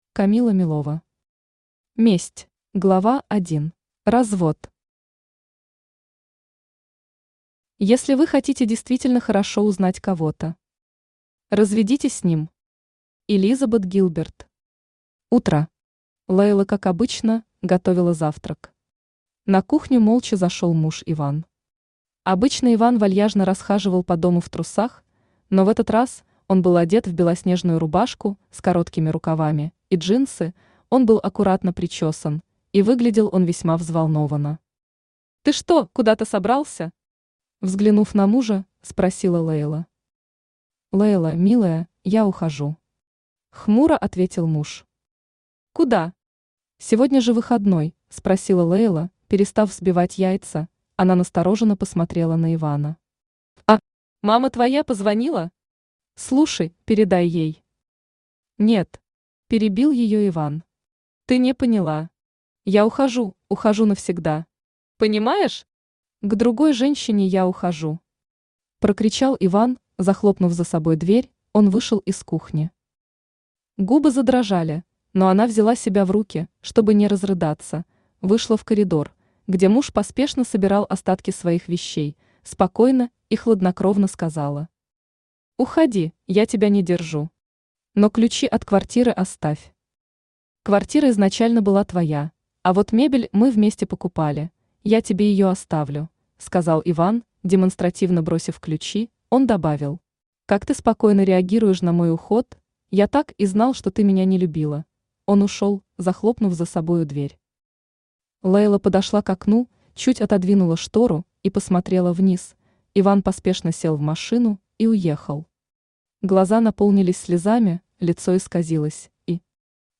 Aудиокнига Месть… Автор Камила Милова Читает аудиокнигу Авточтец ЛитРес.